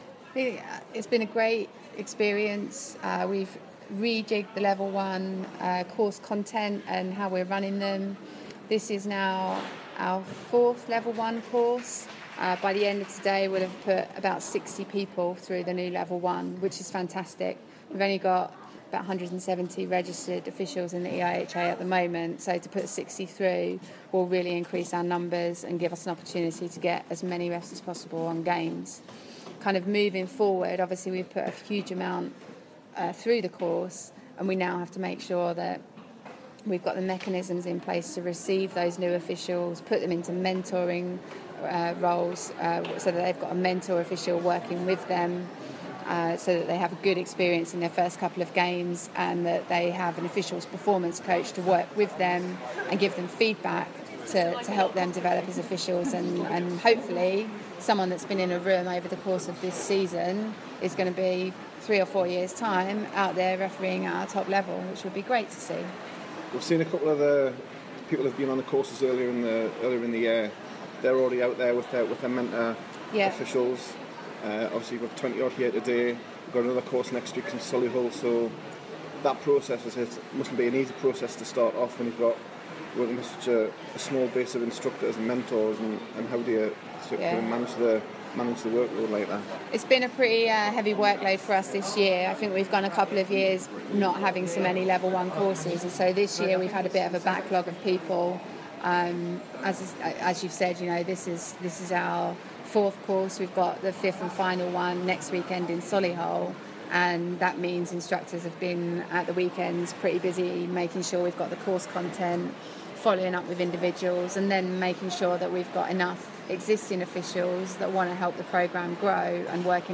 spoke at the latest Level 1 referee camp